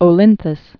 (ō-lĭnthəs)